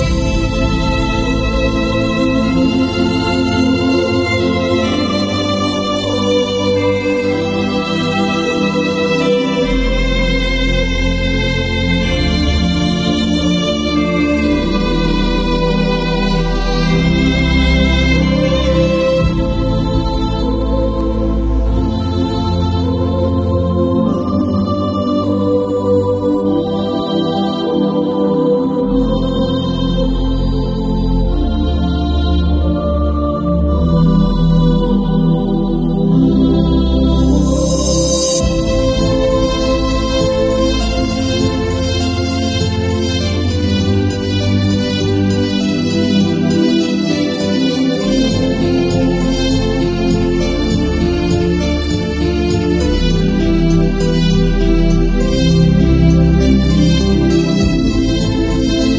Feel the tension leave your body as you listen to this 174 Hz healing frequency ease pain and stress 174 Hz is known as a natural anaesthetic relieving tension and physical discomfort.